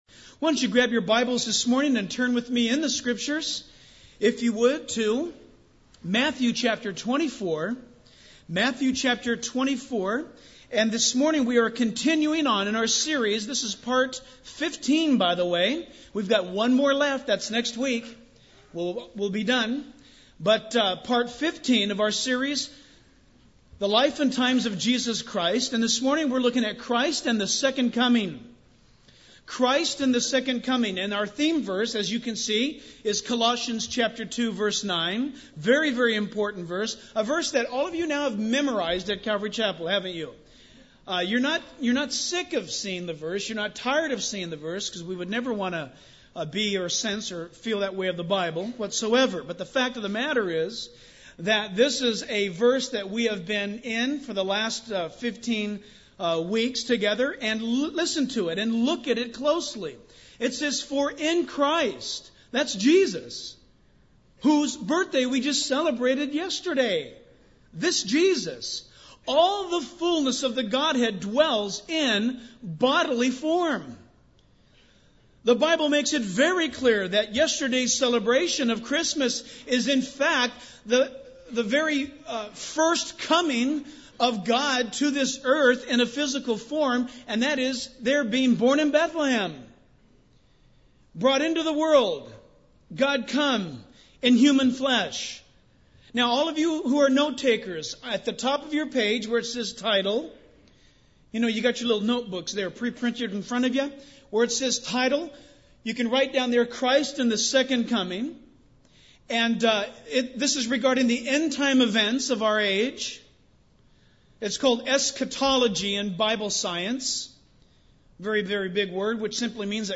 In this sermon, the speaker begins by enthusiastically describing a recent church service that was filled with powerful music and worship, leading to many people accepting Jesus.